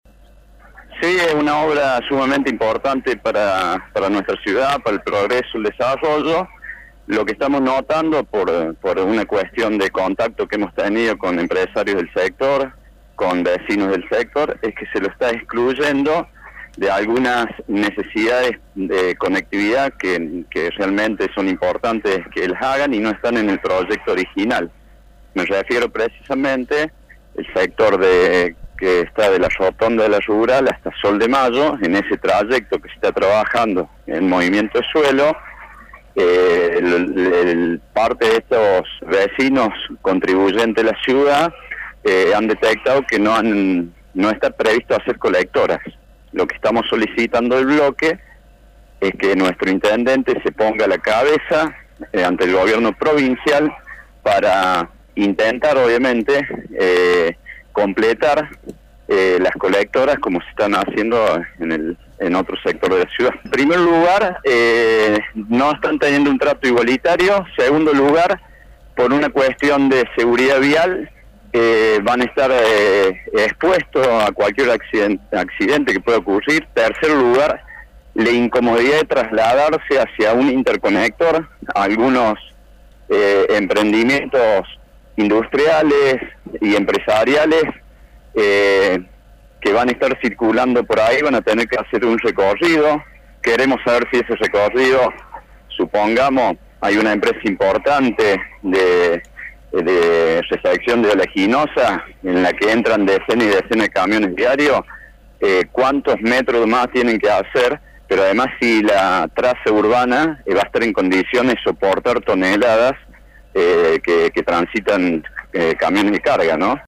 Pablo Benítez, concejal de la oposición, dijo que se trata de una obra muy importante, pero que hay algunas planificaciones que no son tenidas en cuenta en el proyecto original.